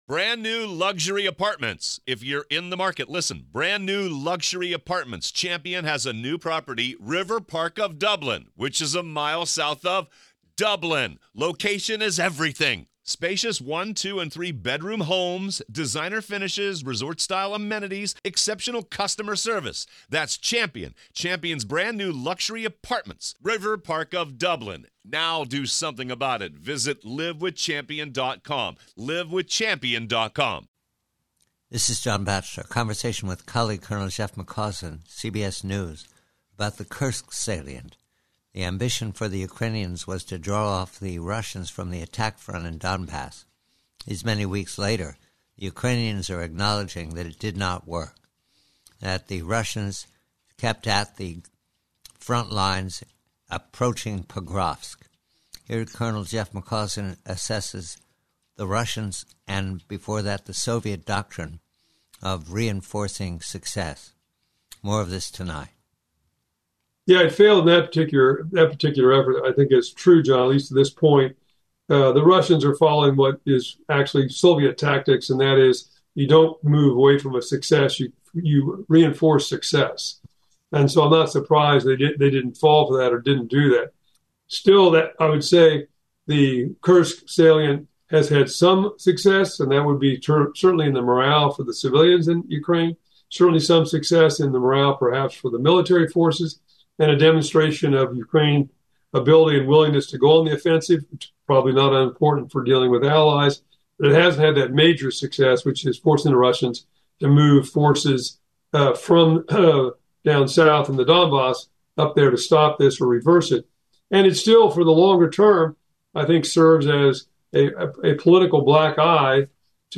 PREVIEW: UKRAINE: FAILURE: Conversation